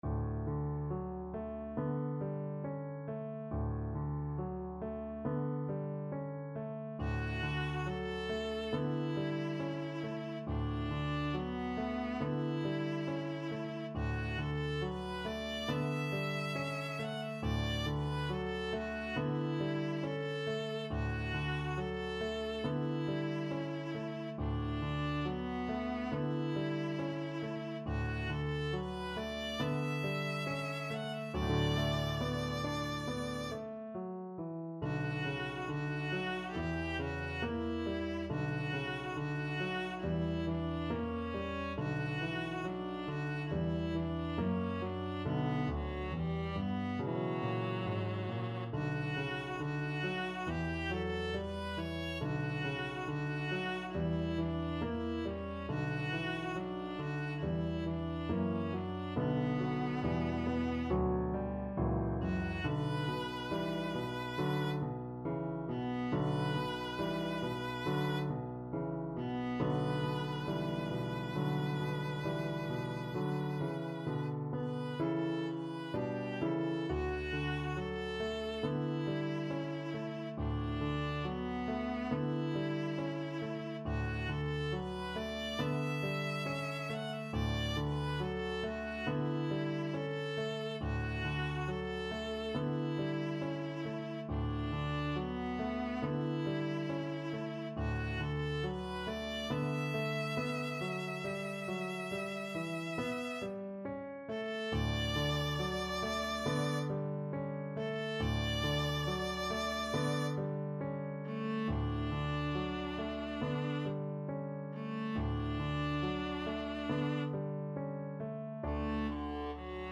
~ =69 Poco andante
4/4 (View more 4/4 Music)
Classical (View more Classical Viola Music)